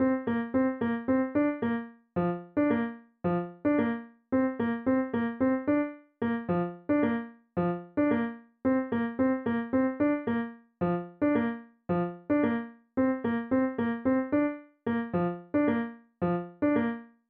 Hey guys, really need help finding this rock tune!